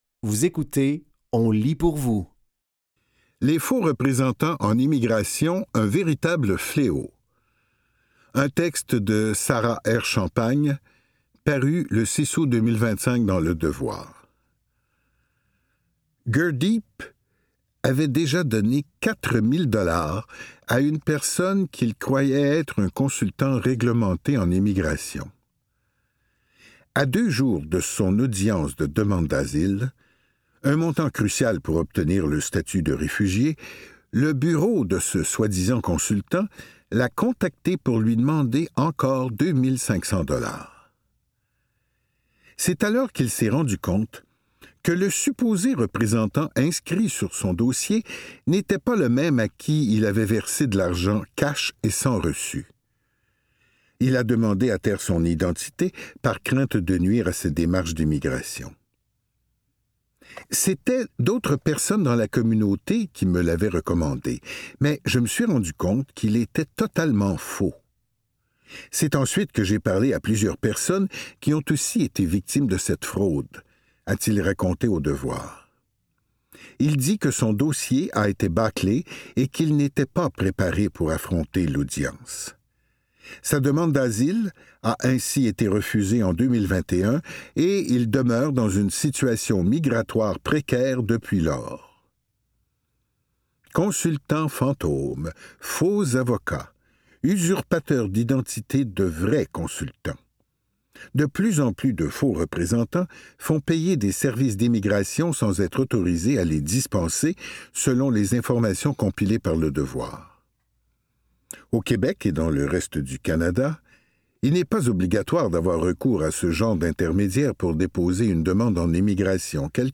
Dans cet épisode de On lit pour vous, nous vous offrons une sélection de textes tirés des médias suivants: Le Devoir, La Presse et Québec Science.